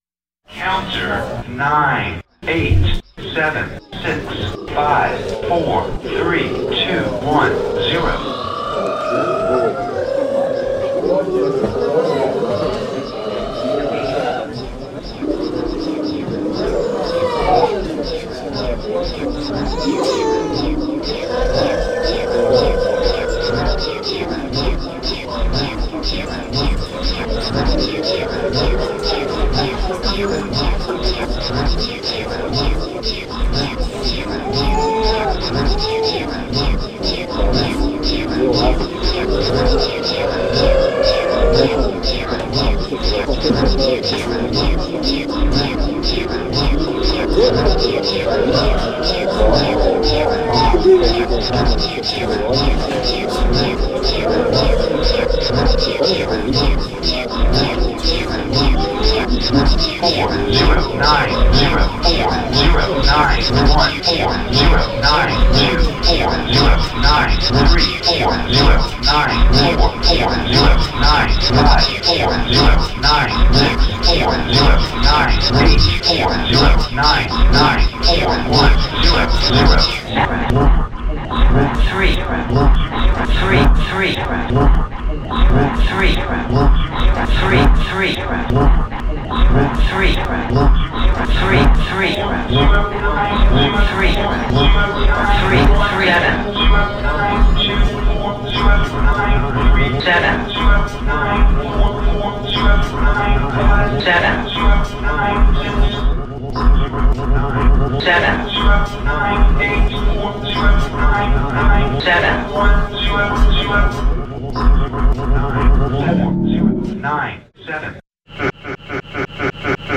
A loud voice would call out four-digit numbers followed by a counter number.
The numbers were called out in seemingly random order.
While I was waiting I thought to record the ambience in the room. There were a number of children making noises and I decided to use these mostly for the track. I also used the announcer voice and sliced up the numbers so that I could reconstitute them and use them rhythmically within Ableton Live and with the SparkLE drum machine.
I filled in this missing piece with the SparkLE rhythm and at the very end of the track.